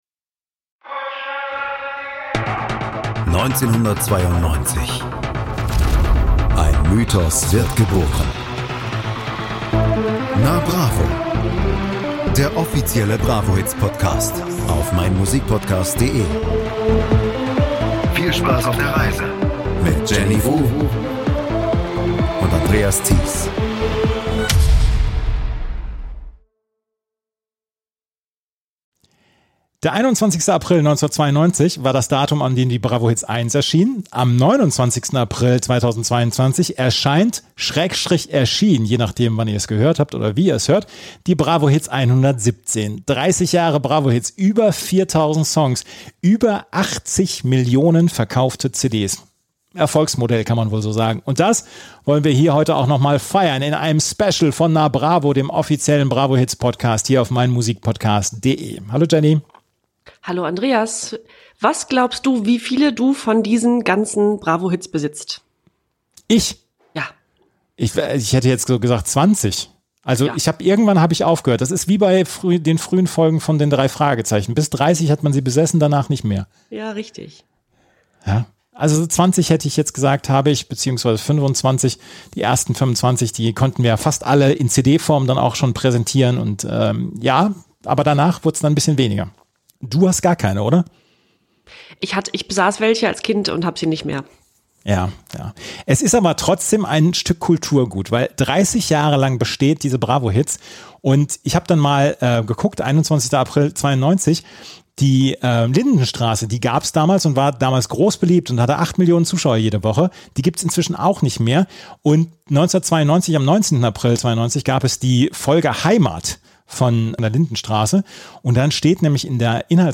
Interview mit Jasmin Wagner